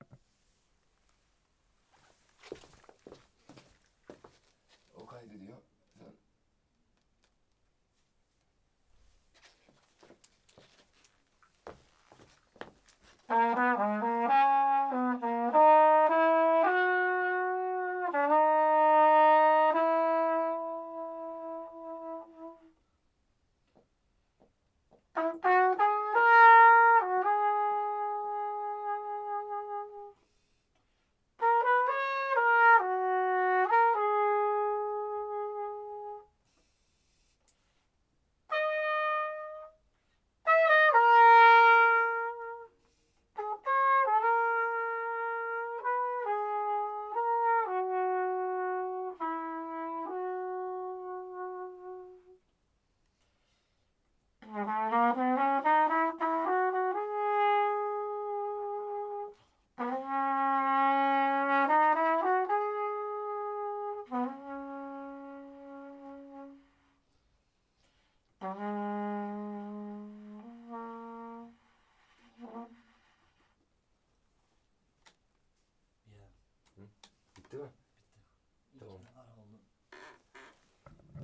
用Zoom H6进行了改进。
标签： 小号 弦秤 即兴 降b 爵士乐 mixolydian 体重秤 模式 独唱
声道立体声